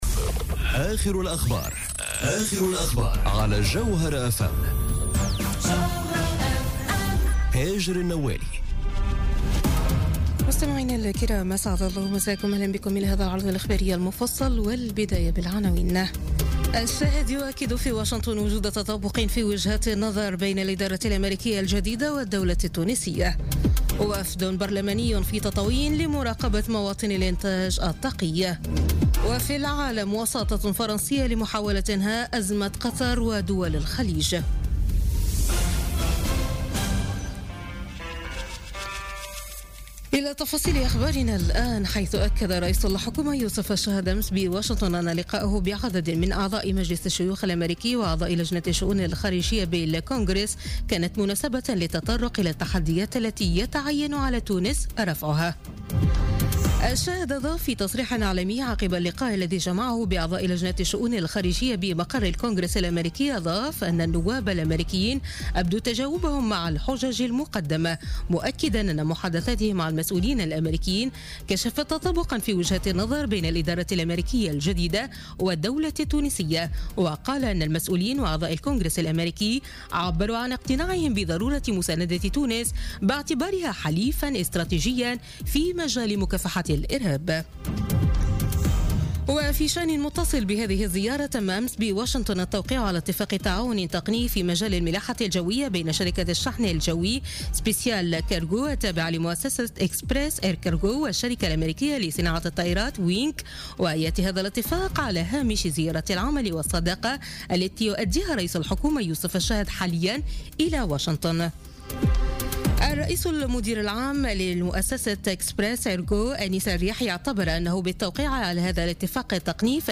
نشرة أخبار منتصف الليل ليوم الخميس 13 جويلية 2017